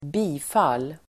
Uttal: [²b'i:fal:]